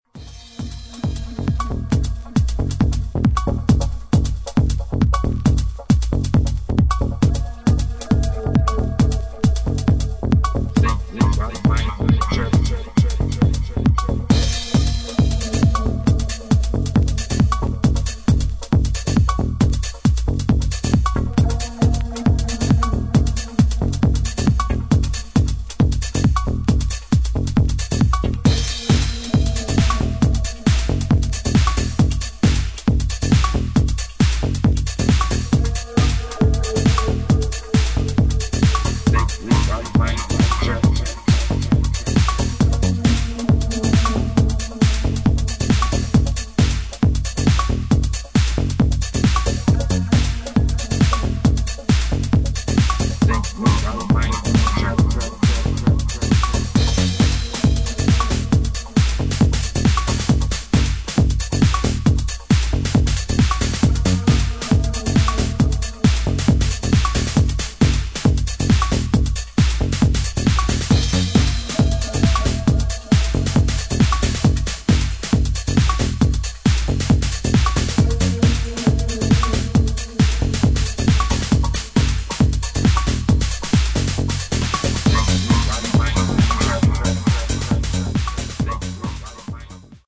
重厚で抜けの良いマシン・グルーヴにヴォイス・サンプルやドープなシンセの上音でアッパーに展開する